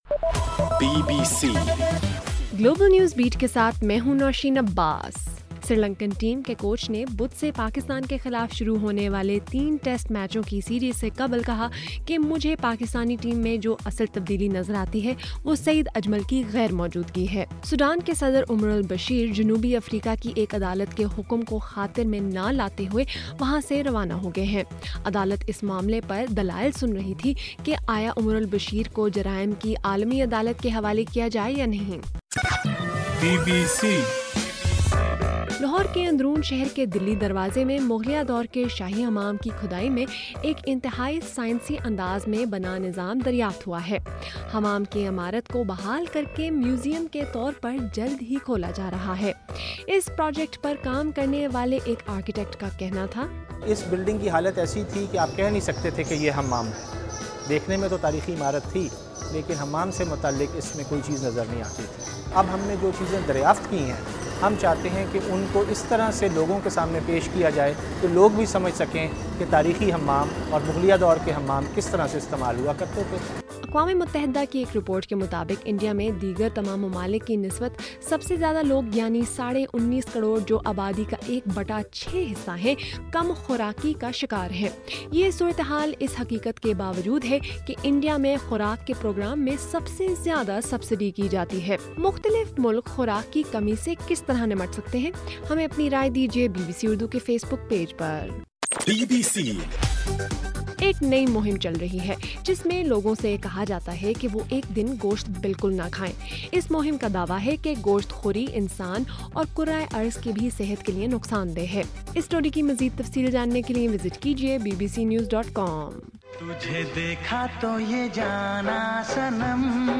جون 15: رات 9 بجے کا گلوبل نیوز بیٹ بُلیٹن